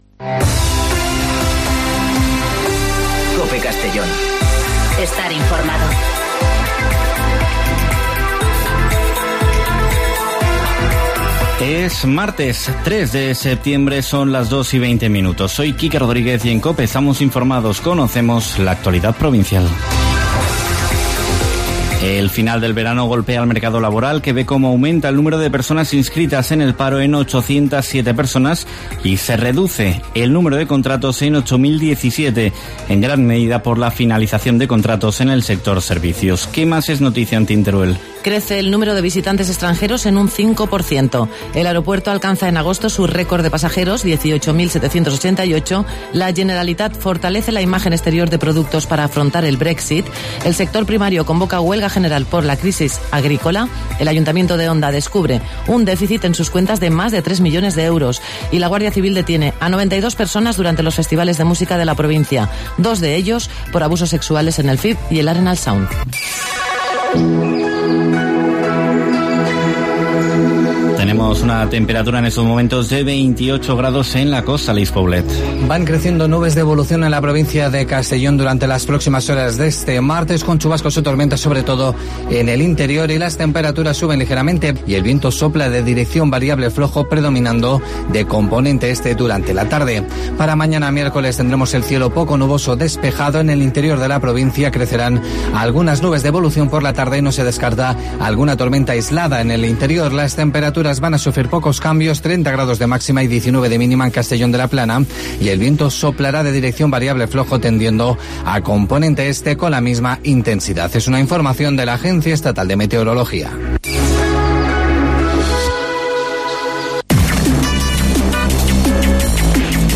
Informativo Mediodía COPE (03/09/2019)